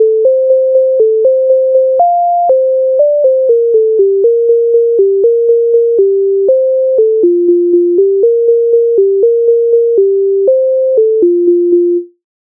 MIDI файл завантажено в тональності F-dur
За городом качки пливуть Українська народна пісня з обробок Леонтовича с. 156